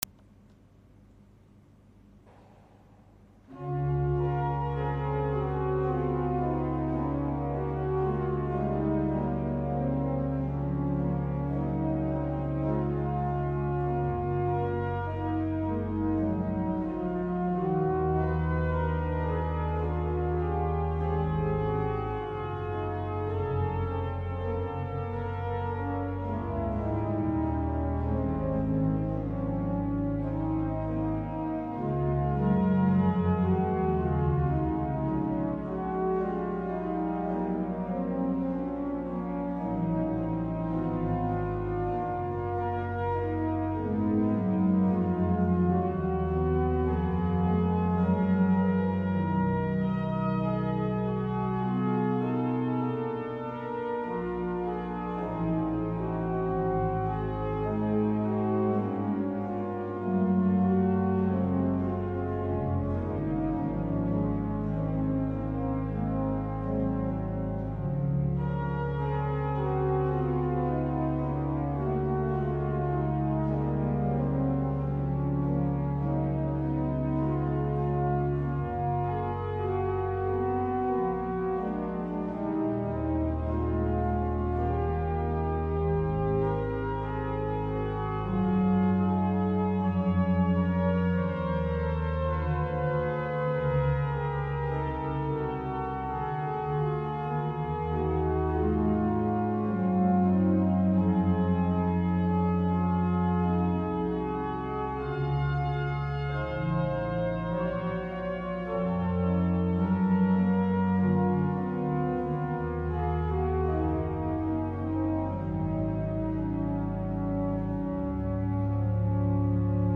L'Organo Rotelli-Varesi della chiesa della missione di S. Vincenzo de' Paoli - Napoli
La registrazione è amatoriale e ha il solo scopo di presentare qualche caratteristica fonica dello strumento.